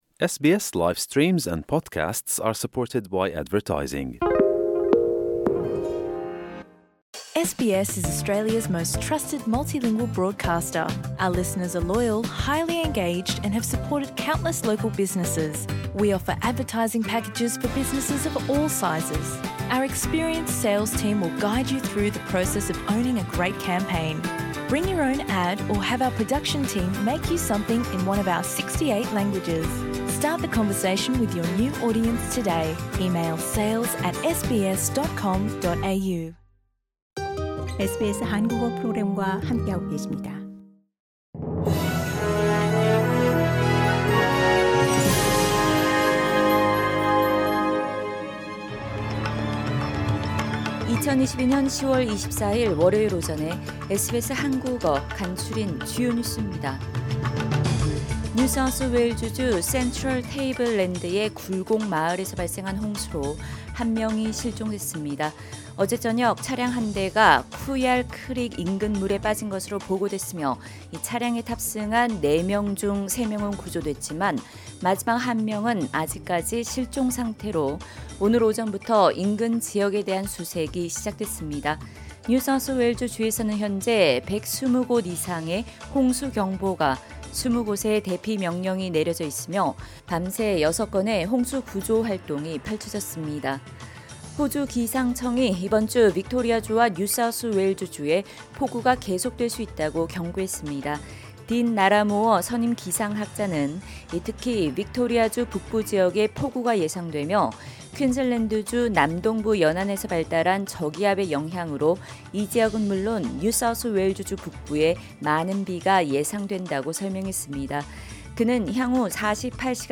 SBS 한국어 아침 뉴스: 2022년 10월 24일 월요일